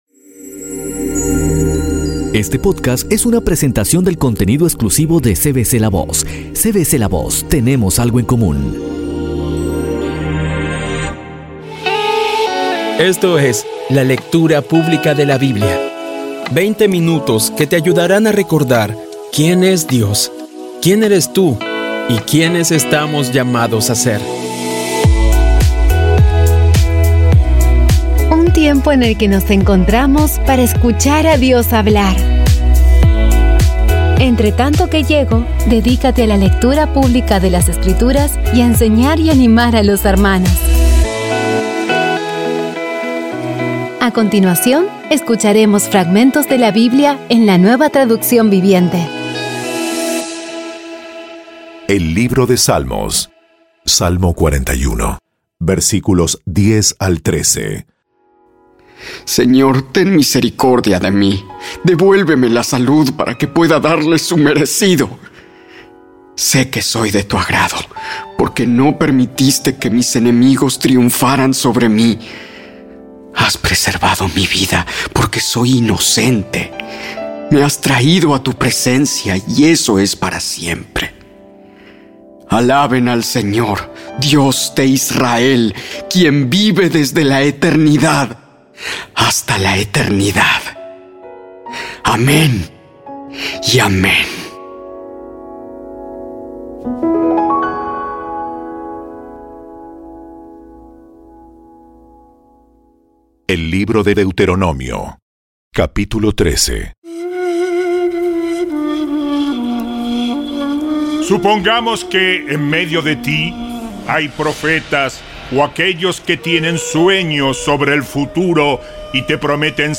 Audio Biblia Dramatizada por CVCLAVOZ / Audio Biblia Dramatizada Episodio 92
Poco a poco y con las maravillosas voces actuadas de los protagonistas vas degustando las palabras de esa guía que Dios nos dio.